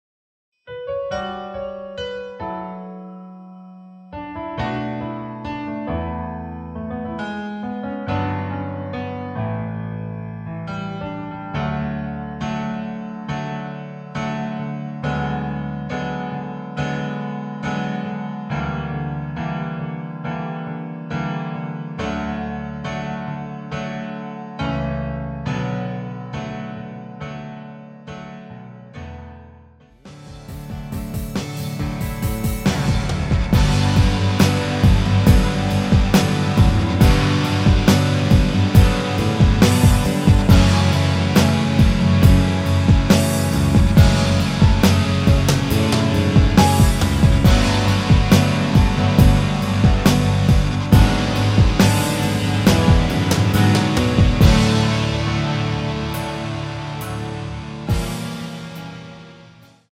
내린 MR 대부분의 남성분들이 부르실수 있는키로 제작 하였습니다.
원곡의 보컬 목소리를 MR에 약하게 넣어서 제작한 MR이며